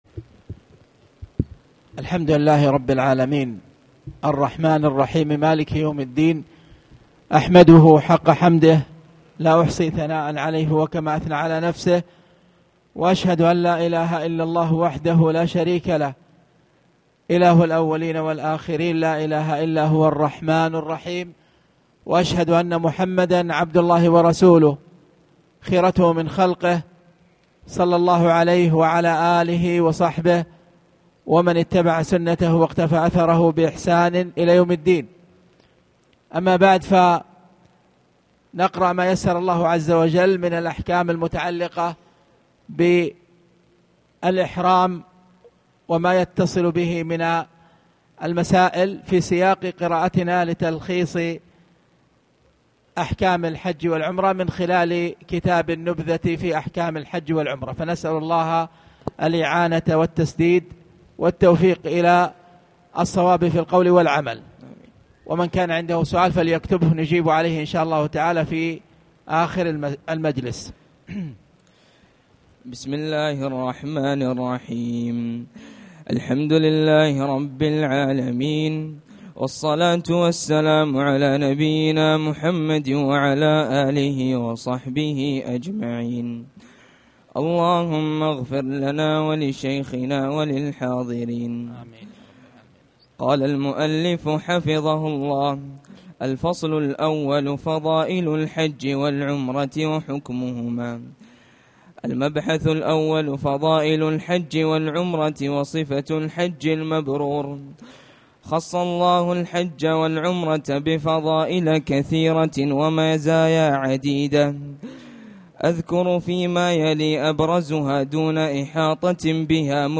تاريخ النشر ٢٨ شوال ١٤٣٨ هـ المكان: المسجد الحرام الشيخ